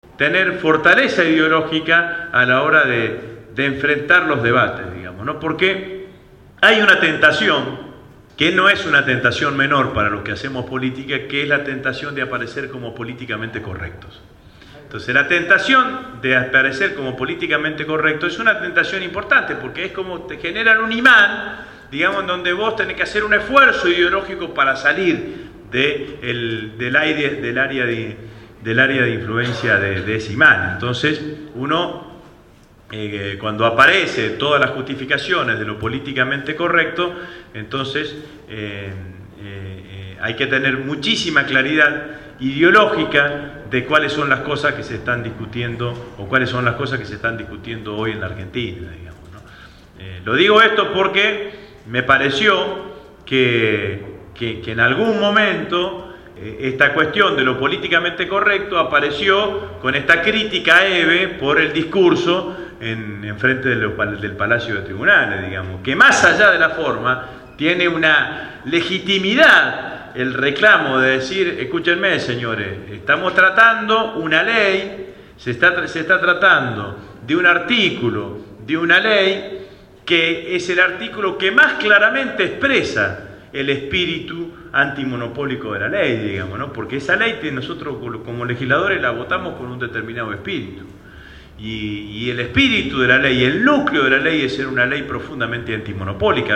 El último 4 de Octubre, organizado por la agrupación «Todas con Cristina«, estuvo presente en el espacio de la Radio Gráfica Agustín Rossi, Presidente del Bloque del FPV en la cámara baja.
Casi dos horas de charla-debate sirvieron para dejar satisfecha a la concurrencia, responder inquietudes y plantear nuevos desafios